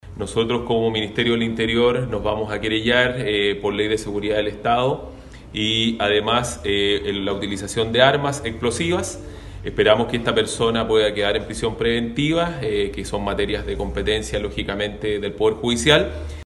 El Intendente César Asenjo, aseguró que el Ministerio del Interior se querellará en contra del detenido invocando la Ley de Seguridad Interior del Estado.